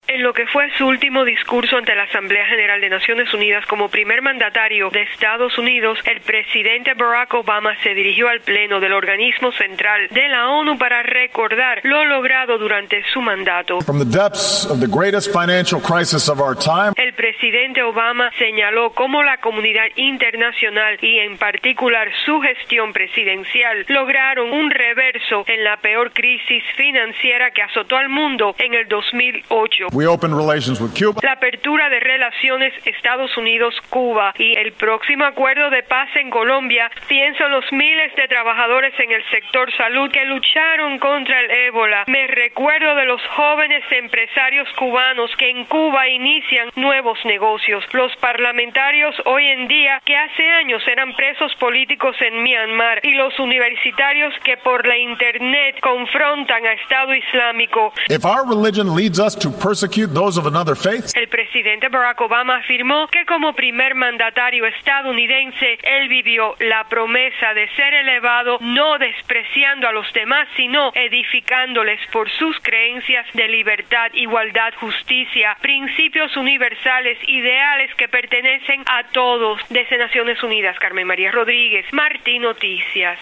En su último discurso ante la Asamblea General de la ONU como Presidente de Estados Unidos, Obama también citó entre los éxitos de su política exterior el acuerdo nuclear con Irán y el apoyo a la transición democrática en Myanmar.